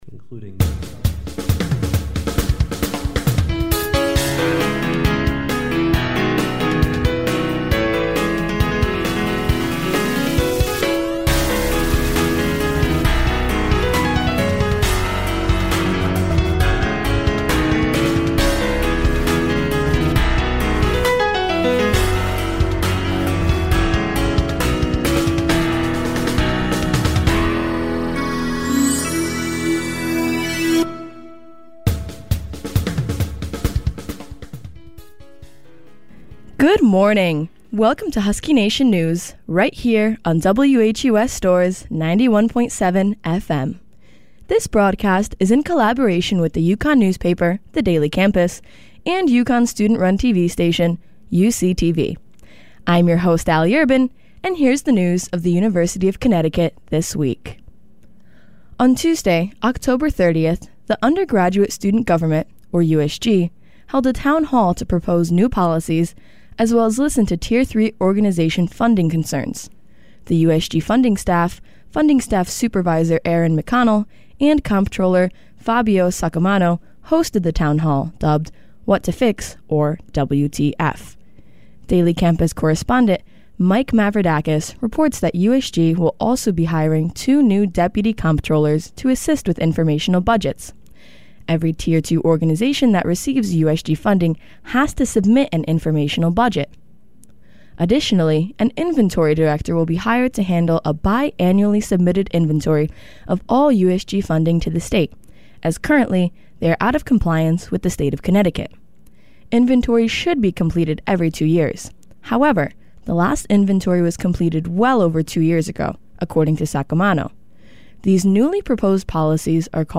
This podcast is a digital archive of the WHUS News Team broadcast which airs on 91.7 FM once a week.